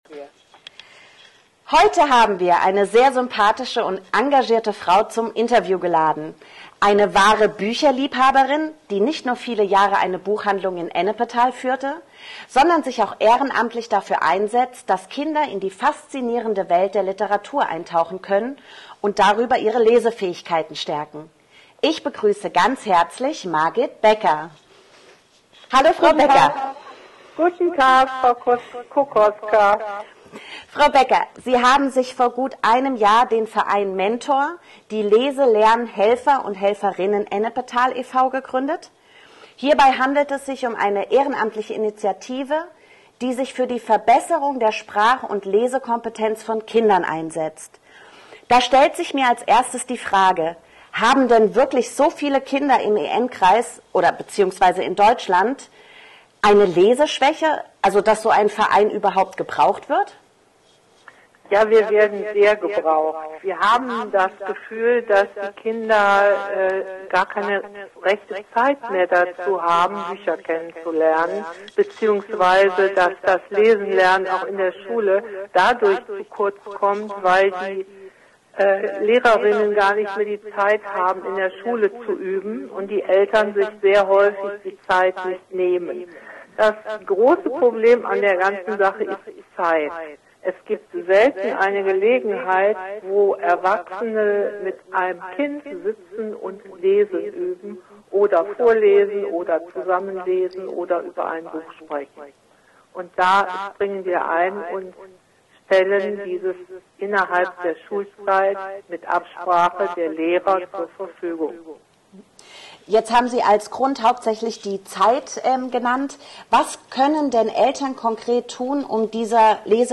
Podcast: Interview